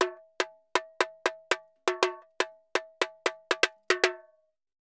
Tamborin Candombe 120_1.wav